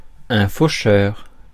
Ääntäminen
Vaihtoehtoiset kirjoitusmuodot faucheux Synonyymit faucheux requin-renard commun Ääntäminen France: IPA: [fo.ʃœʁ] Haettu sana löytyi näillä lähdekielillä: ranska Käännöksiä ei löytynyt valitulle kohdekielelle.